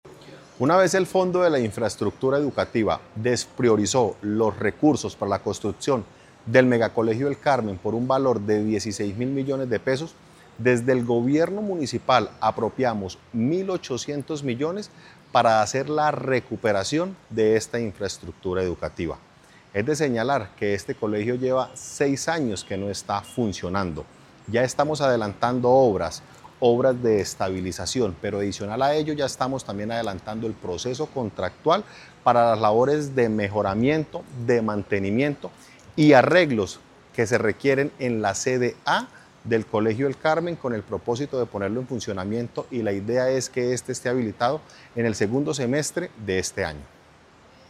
Alcalde de Floridablanca, José Fernando Sánchez